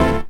12SFX 03  -R.wav